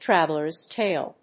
アクセントtráveller's tàle